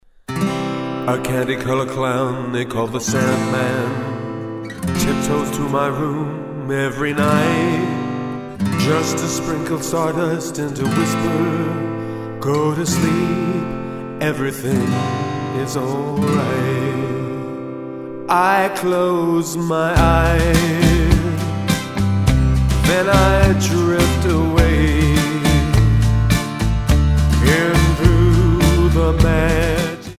Tonart:C Multifile (kein Sofortdownload.
Die besten Playbacks Instrumentals und Karaoke Versionen .